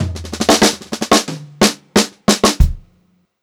92ST2FILL2-R.wav